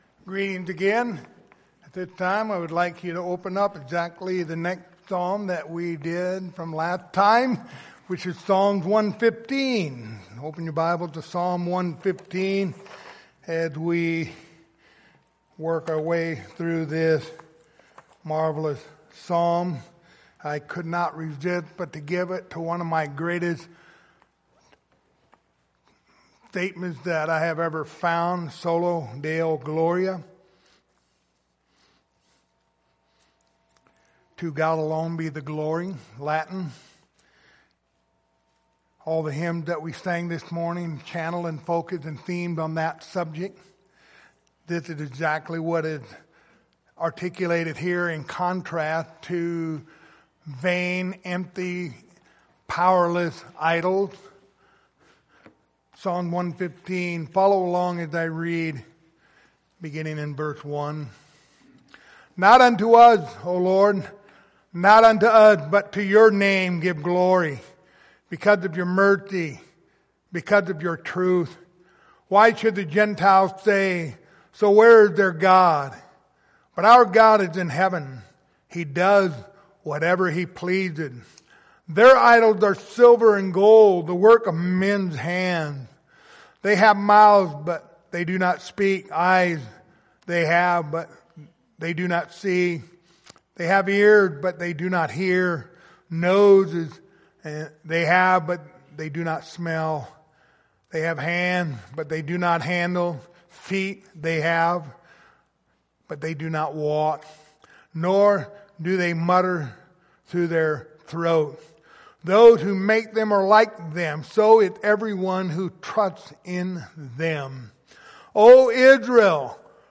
The book of Psalms Passage: Psalms 115:1-18 Service Type: Sunday Morning Topics